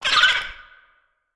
Sfx_creature_babypenguin_shudder_03.ogg